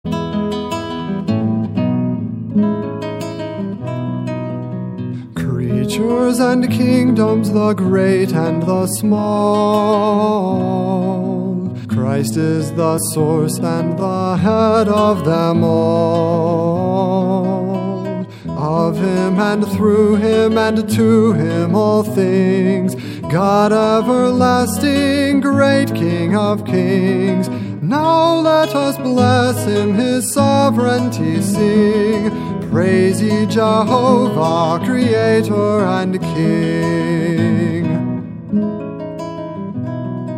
Songs for Voice & Guitar